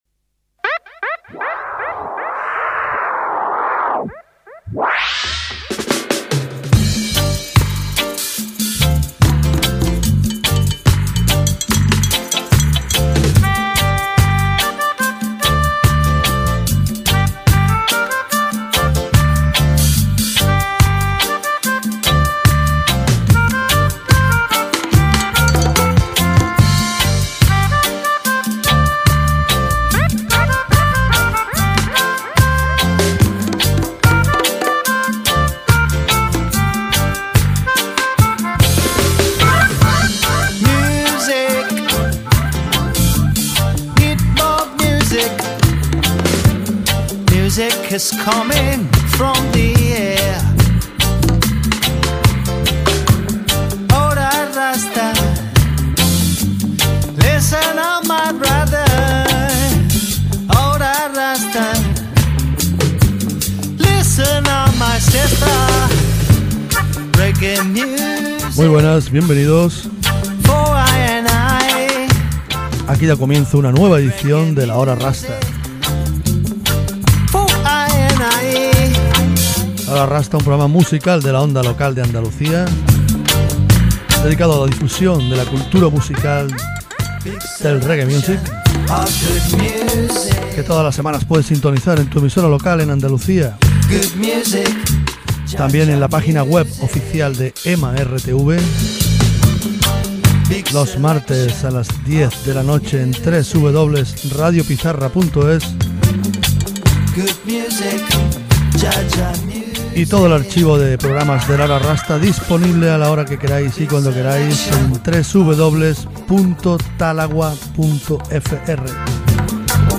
This week a fine seventies seven inches selection